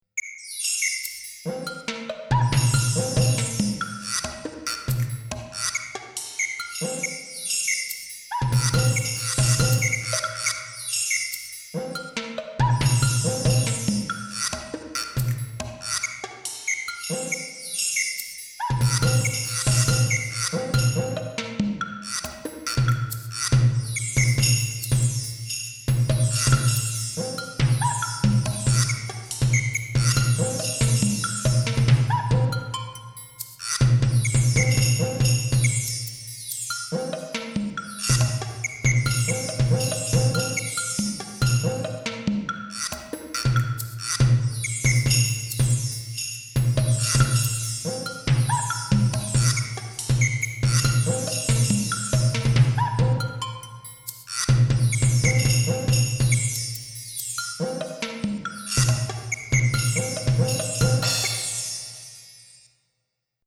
Instrumental music
computer
electronic
percussion